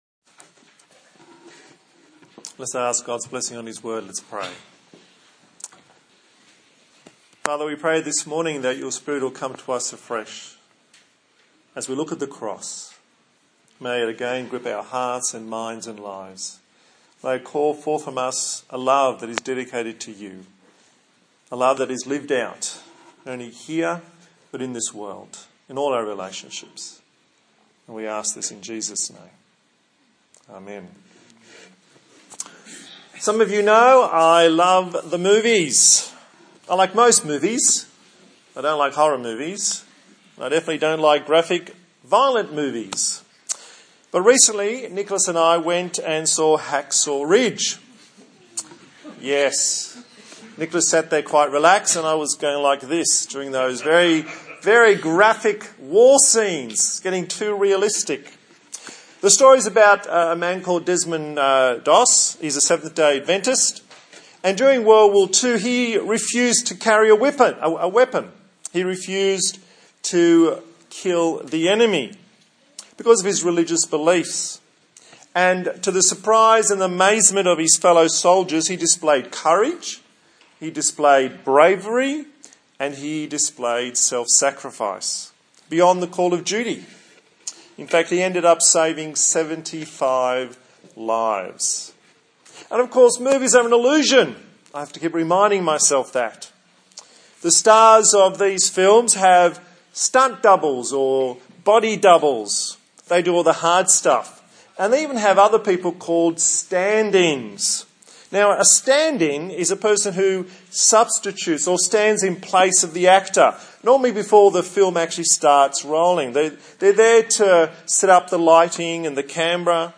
Good Friday Passage: Mark 15:1-20 and 21-47 Service Type: Sunday Morning A Good Friday sermon from the book of Mark